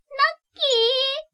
「喜」のタグ一覧
ボイス
女性